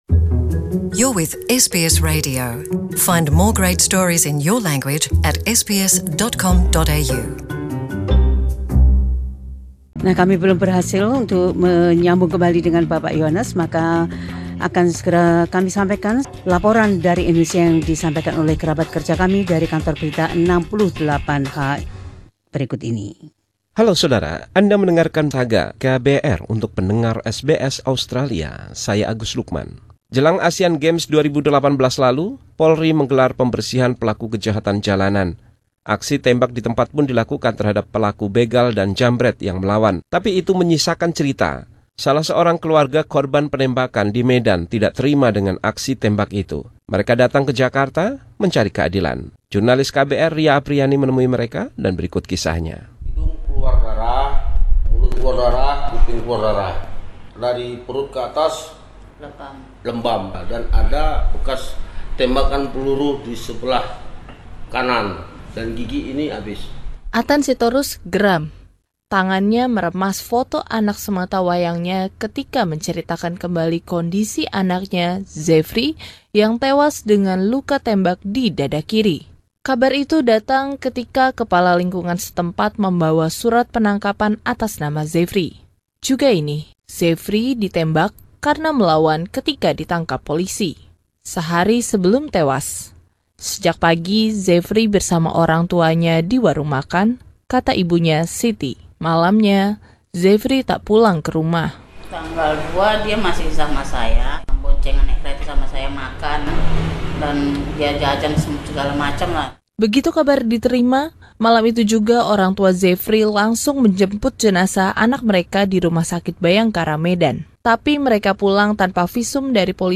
This special report from the team at KBR 68 H tells the story of a family from Medan who believe their son was unjustly killed by the police.